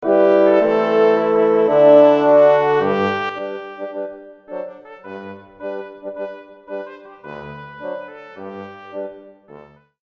Ascolta le due sezioni e cogli la differenza di intensità: int_04.mp3